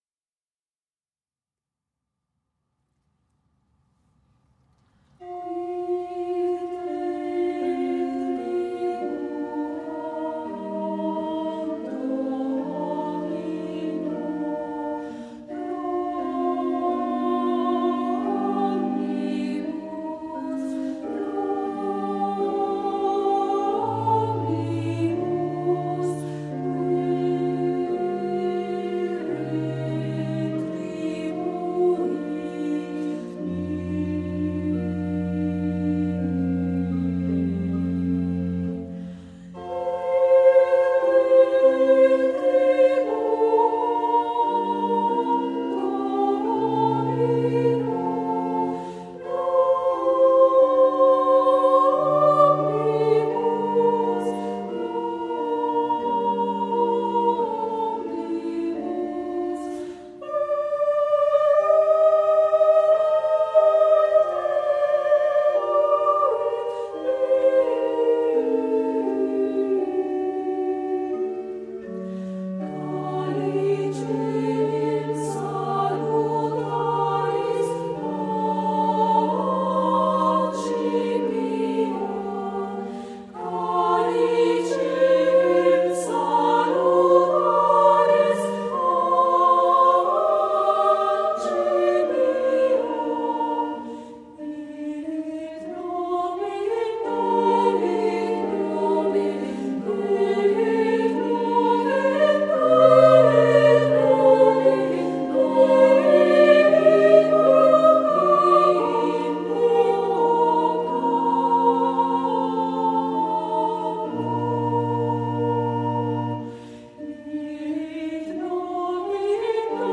gruppo vocale